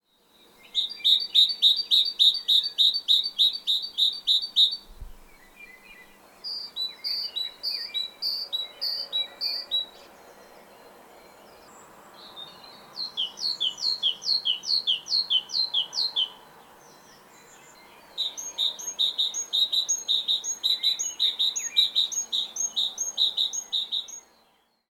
Art: Kjøttmeis (Parus major)
Sang
Lyder: Sangen er variabel, men består av metalliske strofer med to til tre stavelser, for eksempel “ti-tu, ti-tu” eller “ti-ti-ta, ti-ti-ta”.